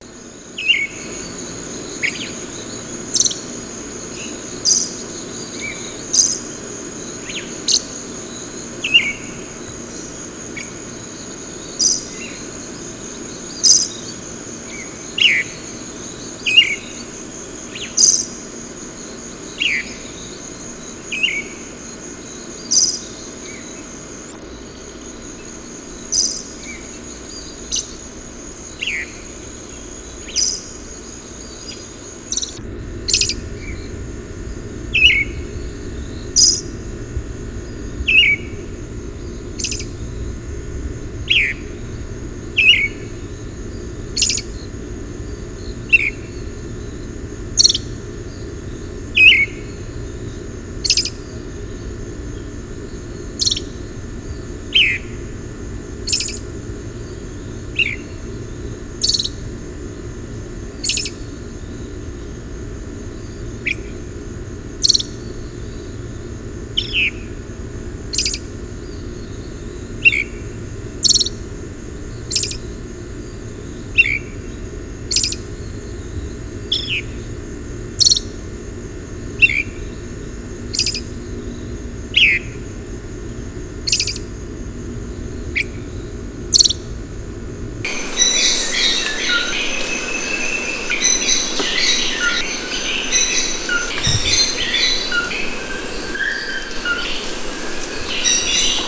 "Zorzal Paticolorado"
"Red-legged Thrush"
Turdus plumbeus
zorzal-patirrojo.wav